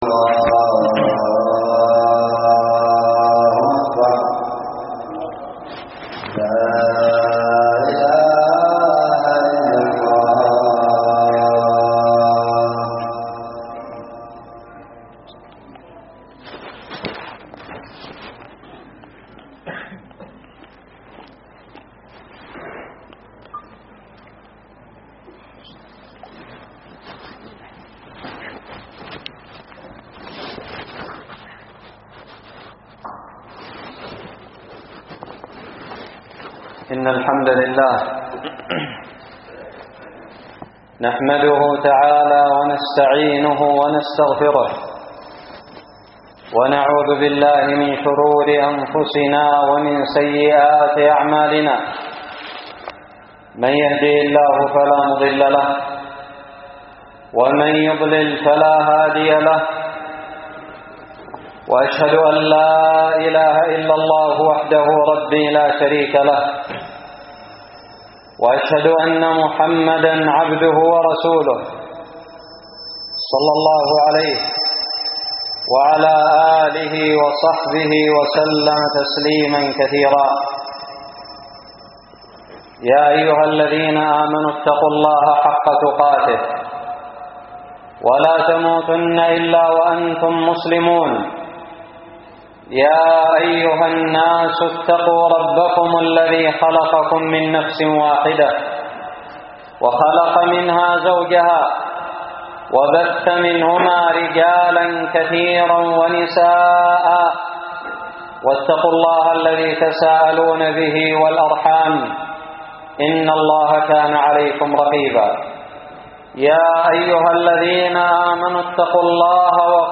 خطب الجمعة
ألقيت بدار الحديث السلفية للعلوم الشرعية بالضالع في 13 رجب 1439هــ